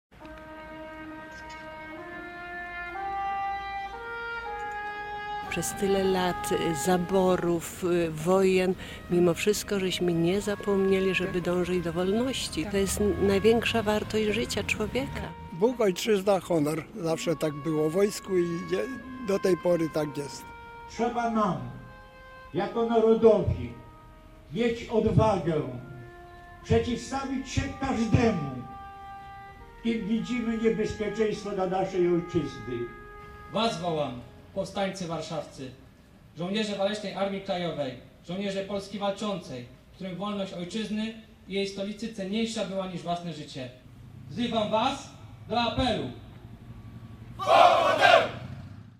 O godzinie „W” w mieście zawyły syreny alarmowe.